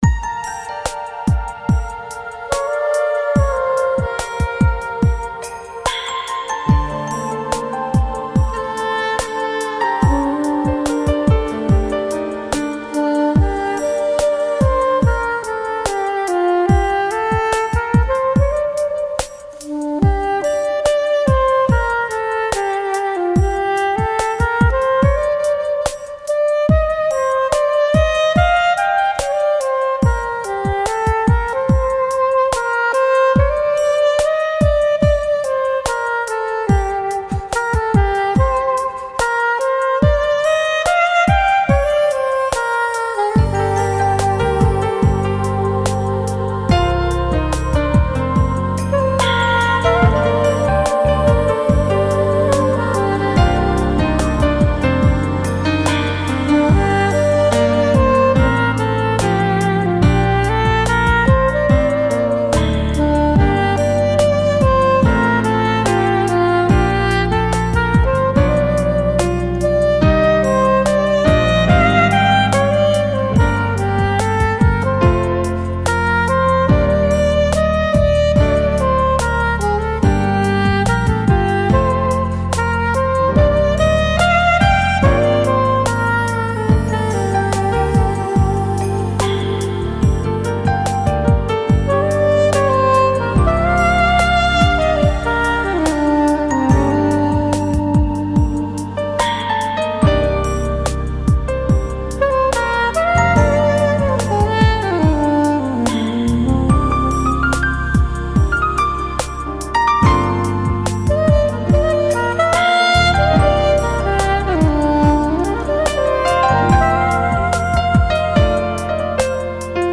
دسته بندی : پاپ